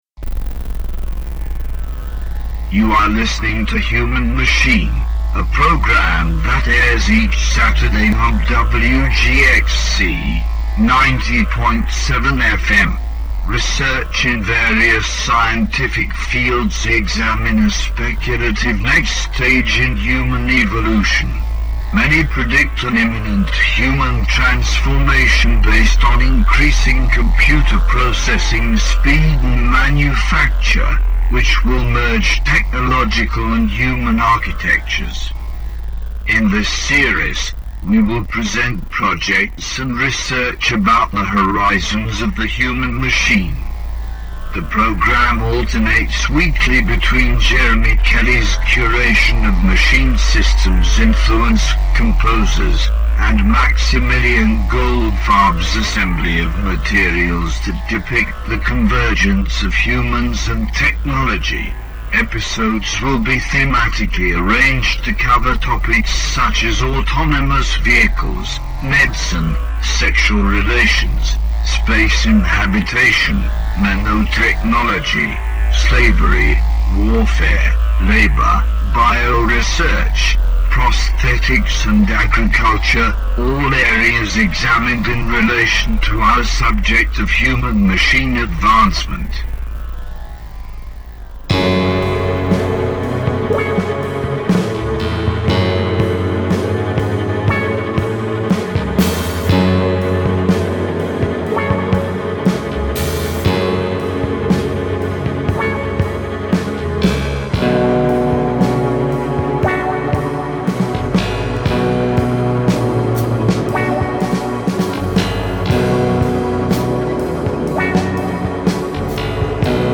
Online Radio